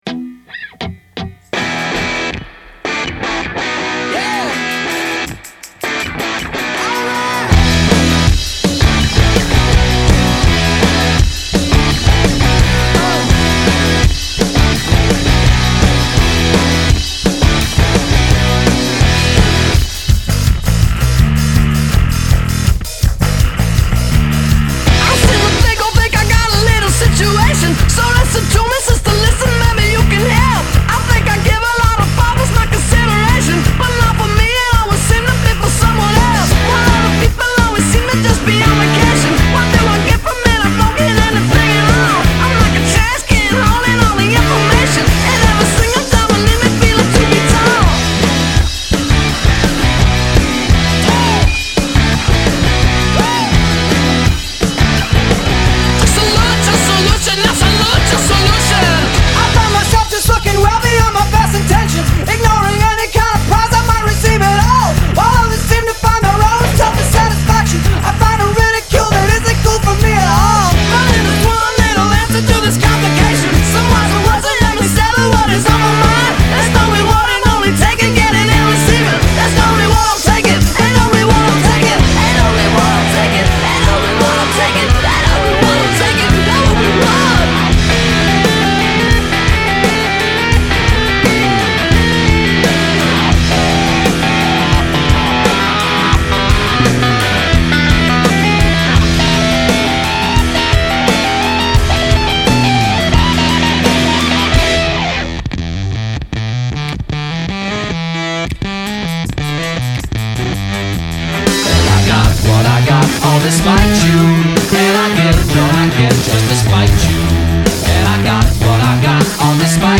Tags: alternative rock random sounds funny sounds